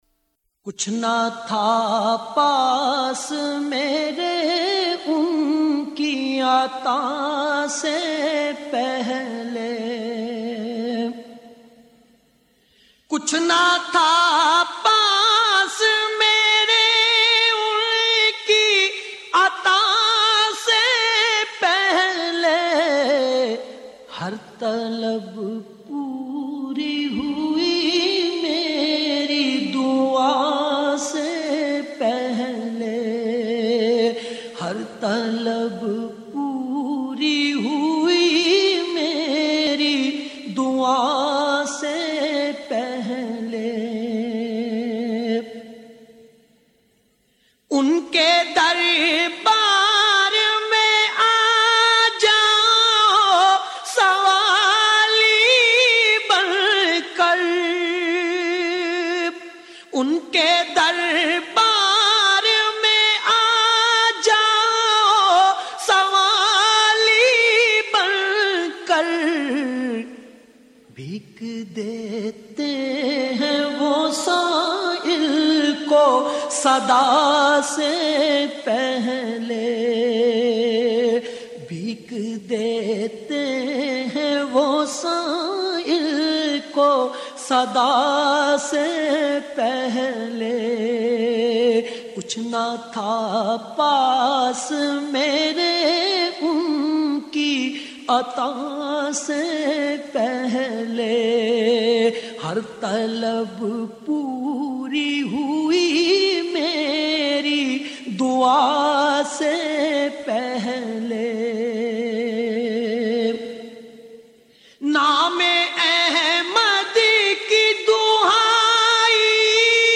نعت رسول مقبول صلّٰی اللہ علیہ وآلہ وسلم